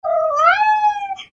Рингтоны без слов , Звуки животных , кошка